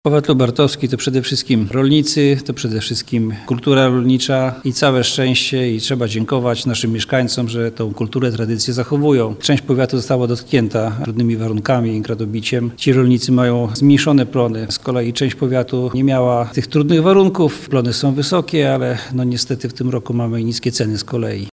– Powiat lubartowski jest rejonem głównie rolniczym, a ten rok nie jest najłatwiejszy dla rolników – mówi starosta lubartowski Jan Sławecki.